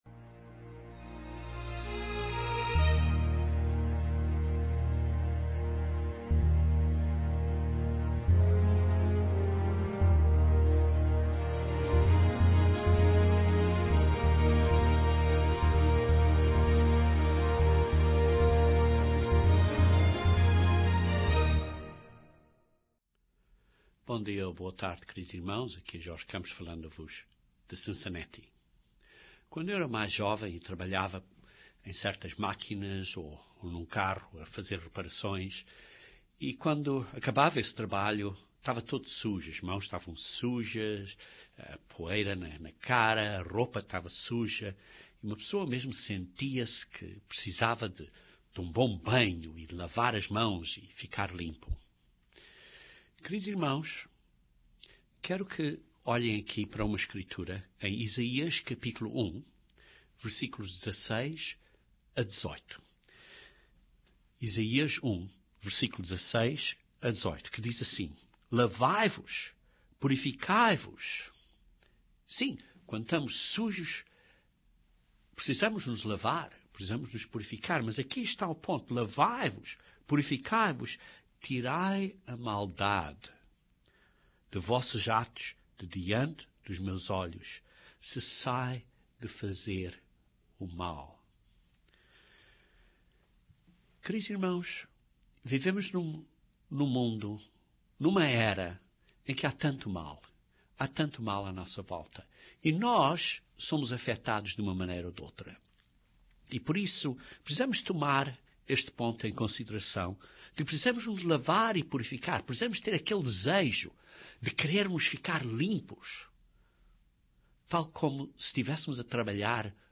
Este sermão aponta para o enorme sacrifício de Jesus Cristo que faz possível que os nossos pecados passados sejam perdoados.